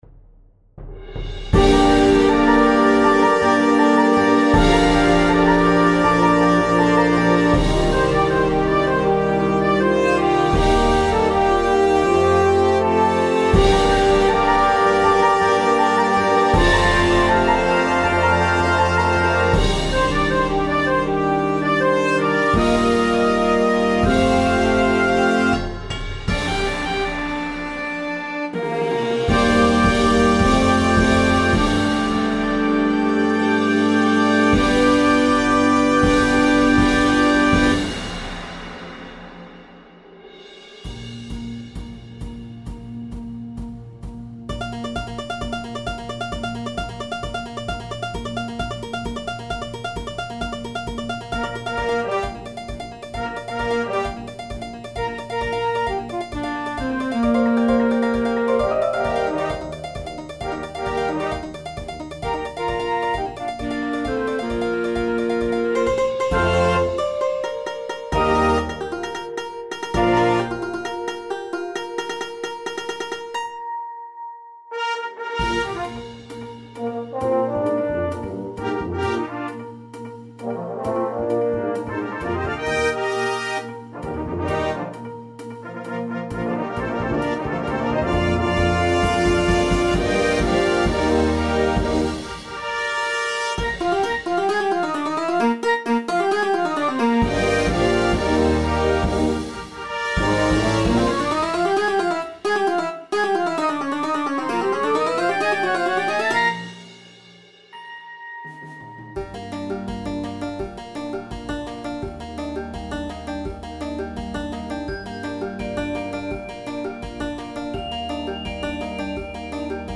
A focused percussion feature
brings rhythmic intensity
With bold musical contrasts and rich textures